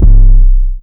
808 (Bang).wav